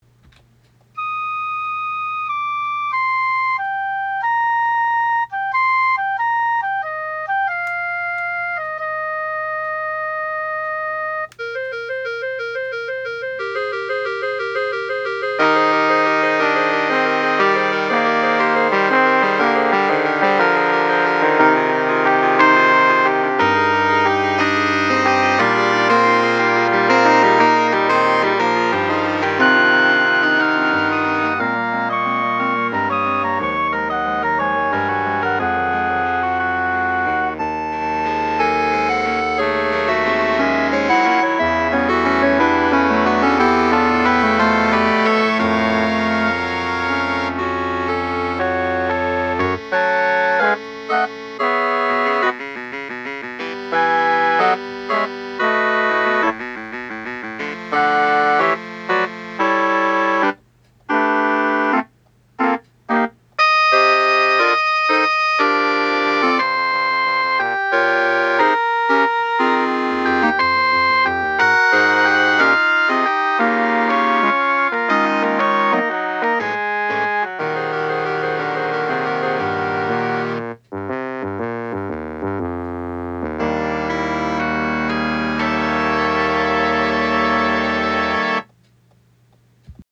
The Gurgling Stream for the Concert Band at Fullbrook School.